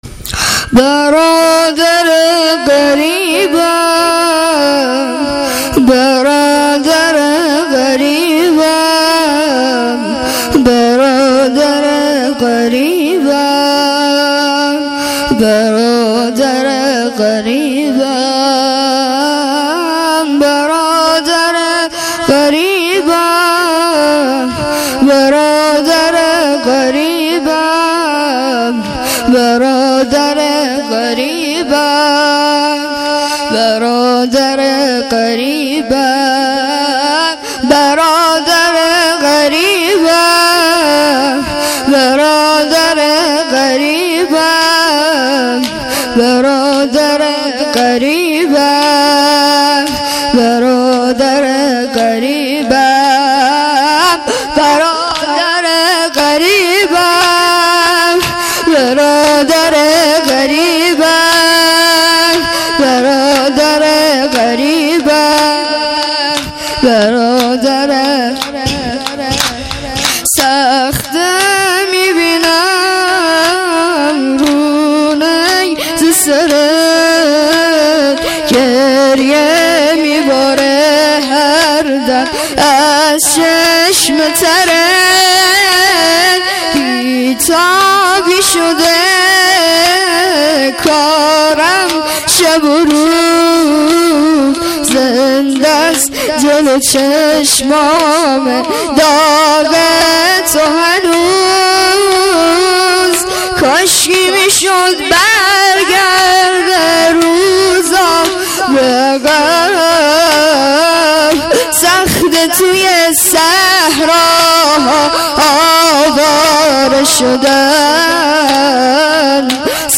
عزای کاروان اسرای کربلا در شهر شام _۹۷